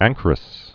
(ăngkər-ĭs)